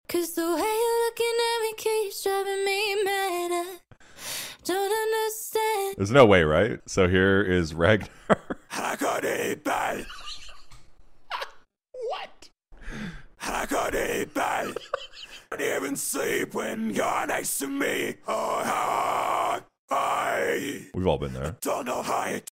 Converting Female Vocals To Ragnar